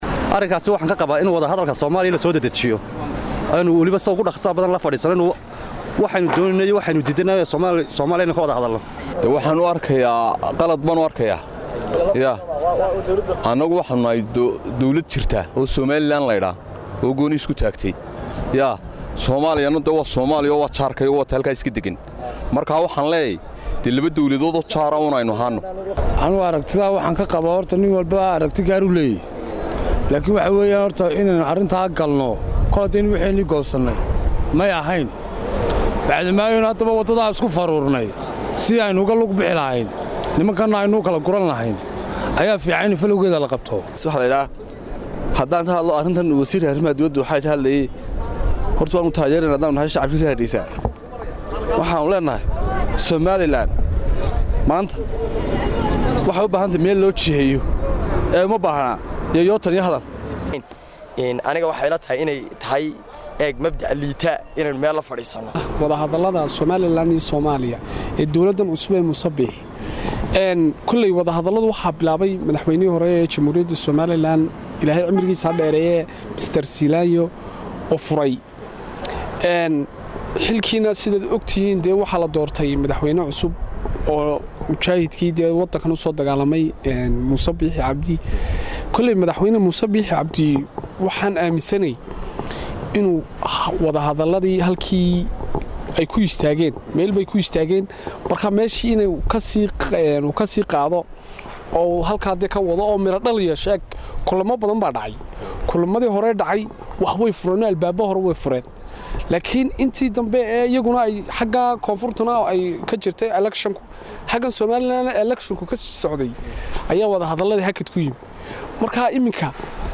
Qaar ka tirsan dadweynaha Hargeysa ayaa fikirkooda ka dhiibtey wadahadalada loo furey ee u dhexeeya Muuse Biixi iyo Farmaajo. Qaar ayaa qaba in faraha laga qaado.